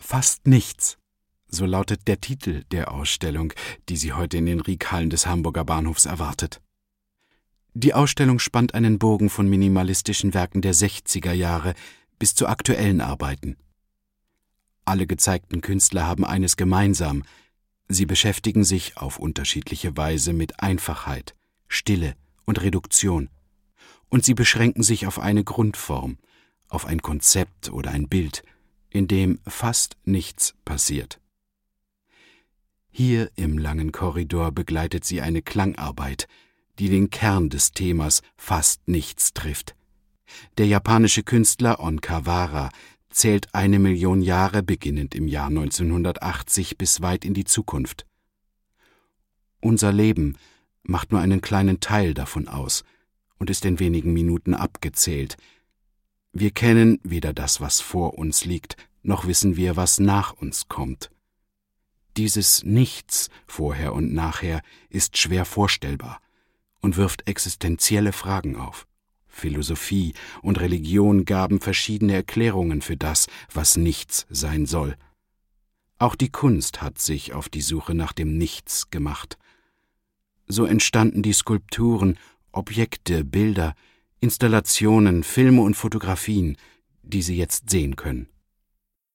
Sprechprobe: Industrie (Muttersprache):